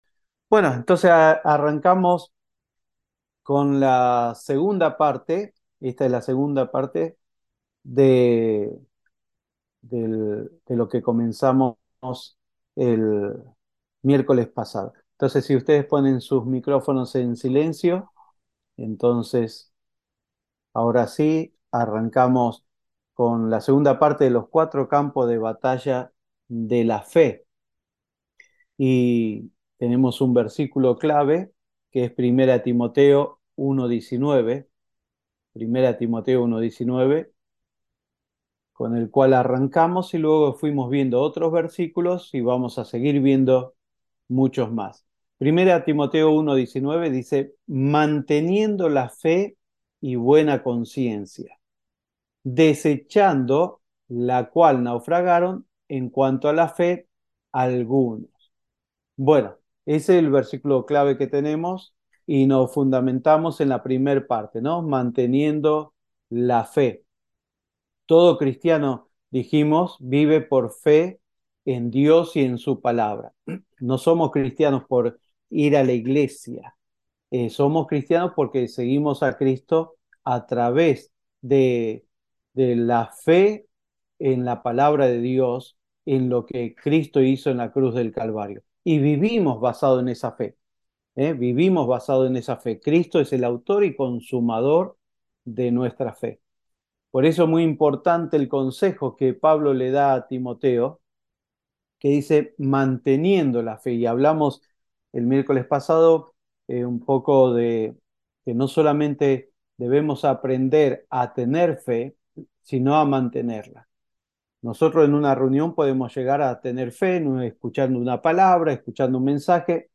Escuche los audios de las enseñanzas dadas vía Zoom.